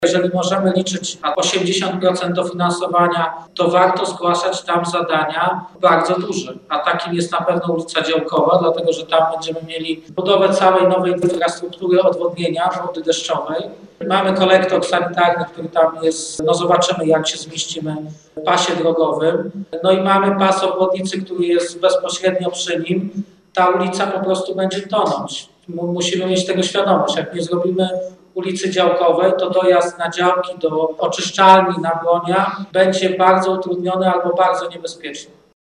Przebudowa jest konieczna, bo już teraz po większych deszczach na ulicy Działkowej tworzą się potężne kałuże, a w związku z budową w pobliżu obwodnicy odpływ wody deszczowej będzie jeszcze bardziej utrudniony. Mówił o tym prezydent Stalowej Woli Lucjusz Nadbereżny: